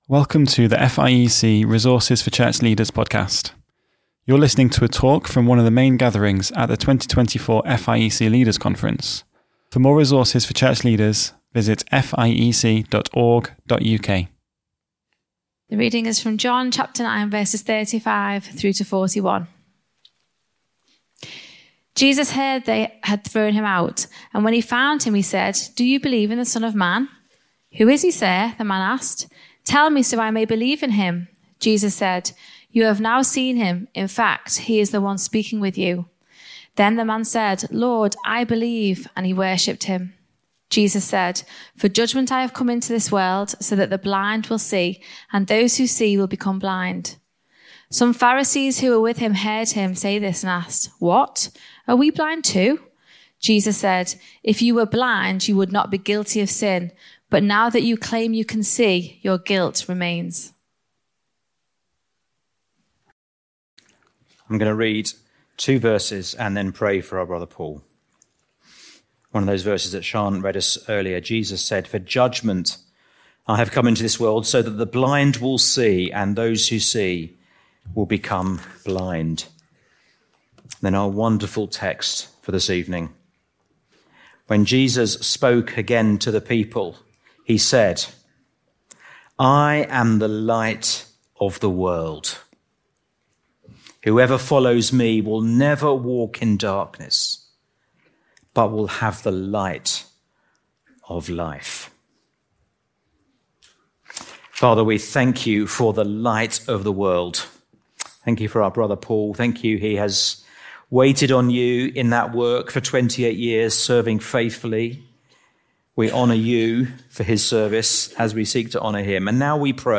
I-am-the-Light-of-the-World-FIEC-Leaders-Conference-2024.mp3